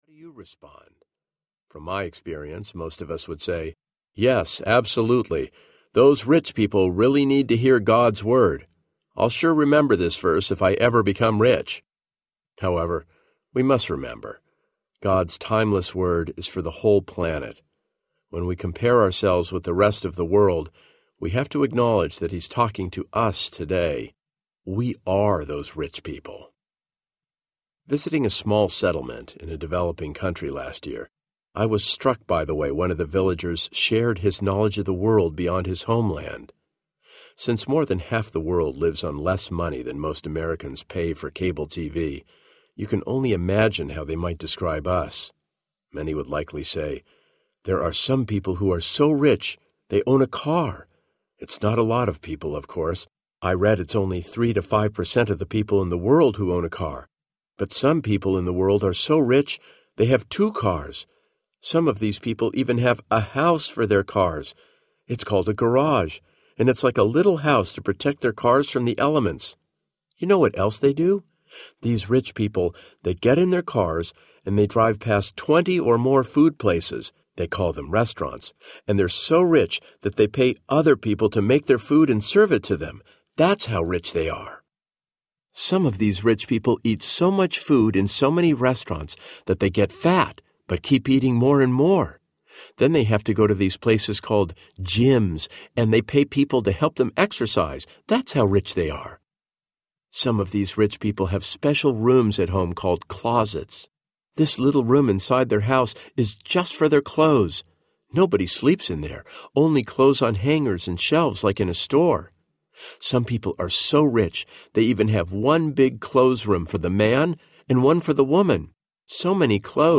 WEIRD Audiobook
Narrator